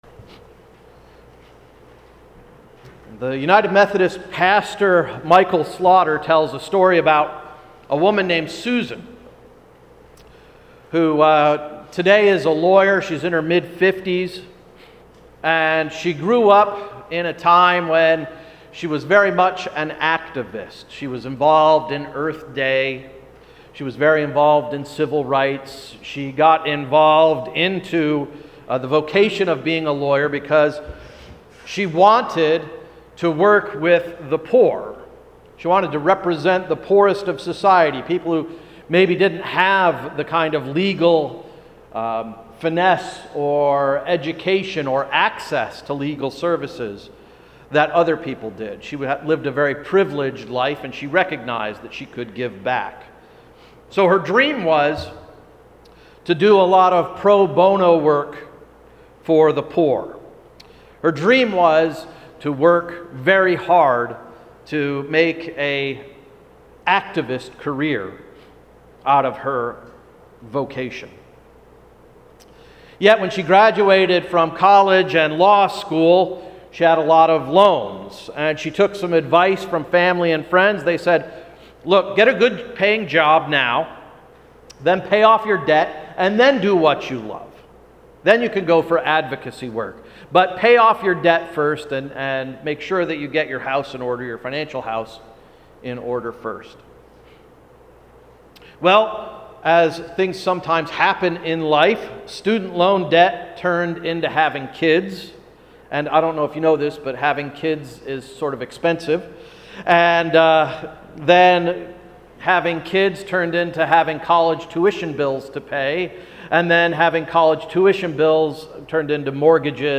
Sermon of May 10th, 2015–“What’s your Passion?” – Emmanuel Reformed Church of the United Church of Christ